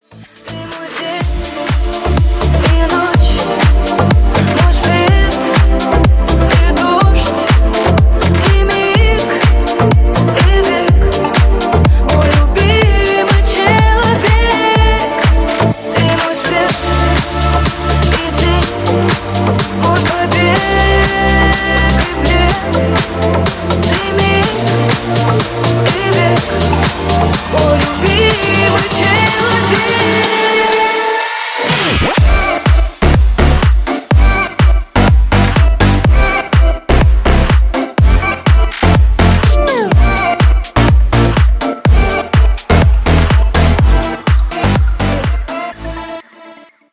• Качество: 128, Stereo
громкие
женский вокал
Club House